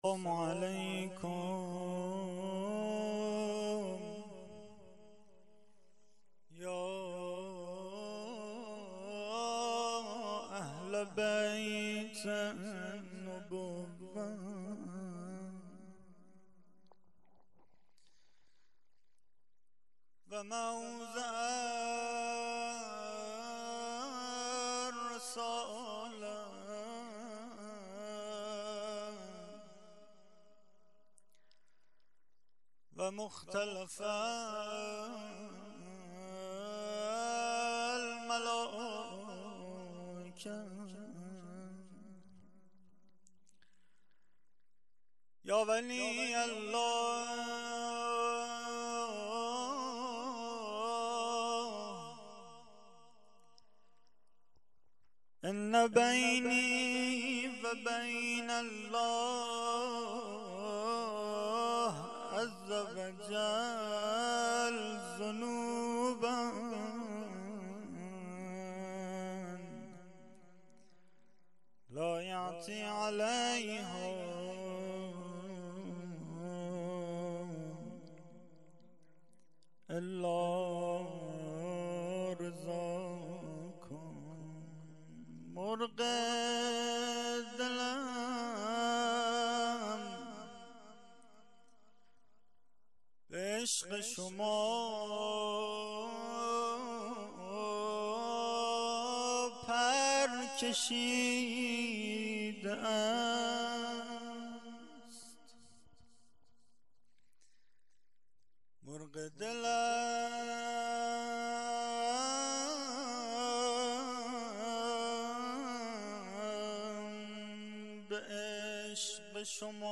rozeh.mp3